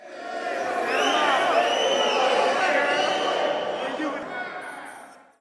cheerful 04